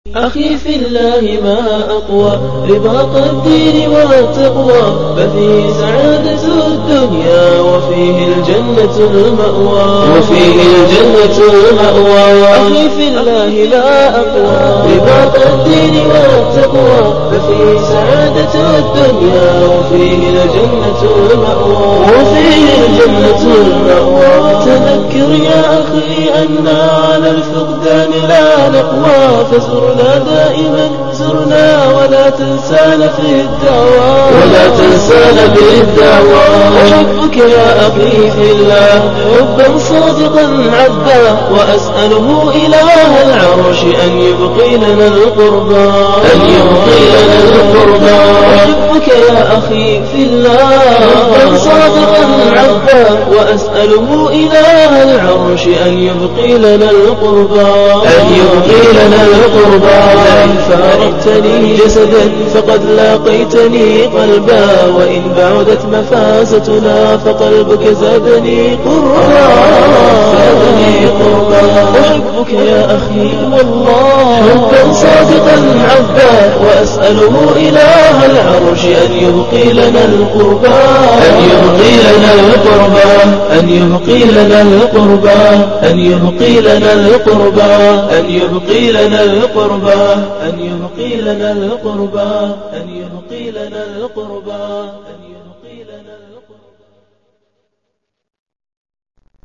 أنشودة ، "أخي في الله ما أقوى" أهديها لكم